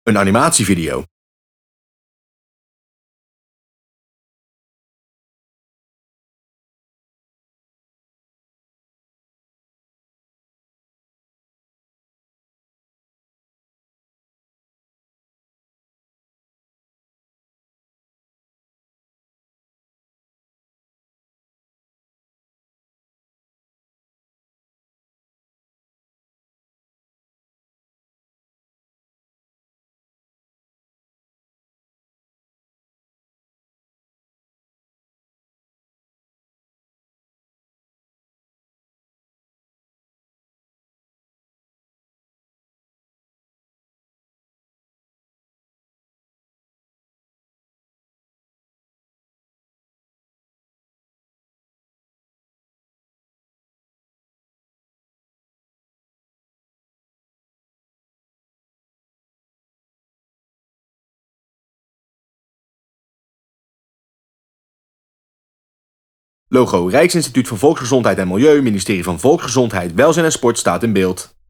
Luchtige muziek speelt
Voiceover: